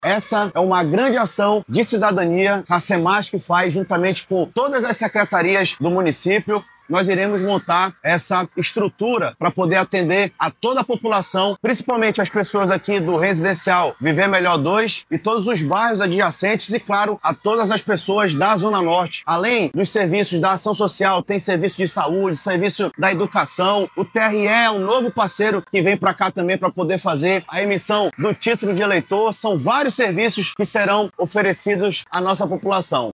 Entre os atendimentos disponibilizados estão a inserção e atualização do Cadastro Único, a emissão de certidões de nascimento, serviços da Amazonas Energia e Águas de Manaus, além de consultas médicas e vacinação, como explica o secretário da Semasc, Saullo Vianna.
SONORA-REPRESENTANTE-SEMASC.mp3